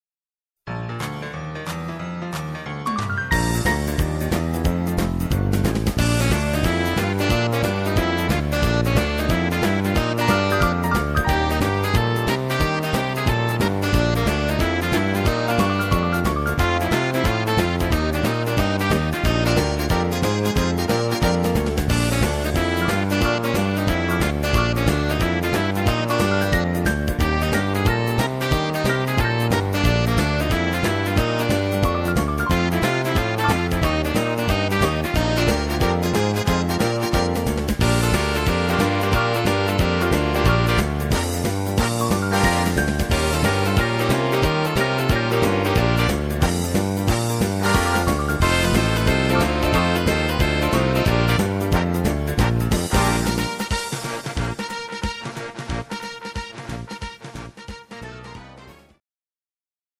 instrumental Big Band